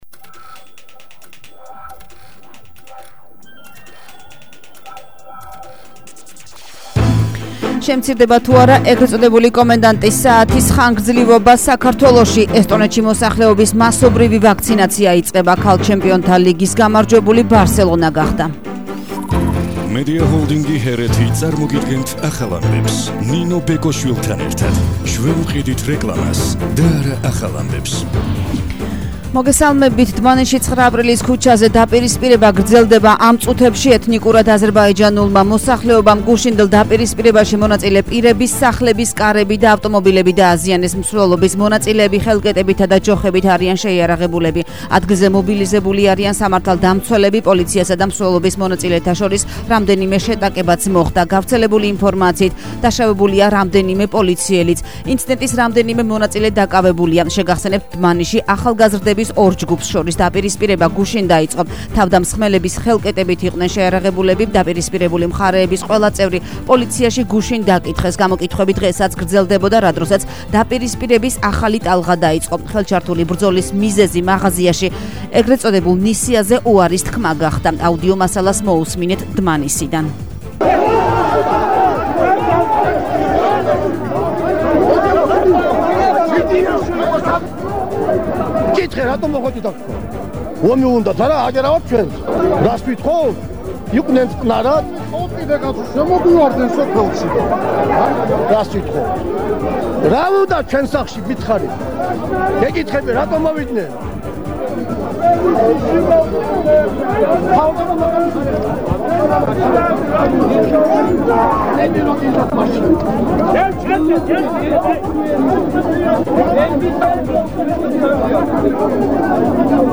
ახალი ამბები 12:00 საათზე –17/05/21 - HeretiFM